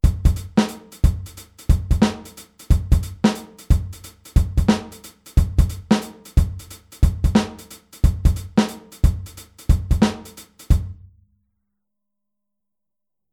Der Handsatz beim Shufflen
Groove19-24tel.mp3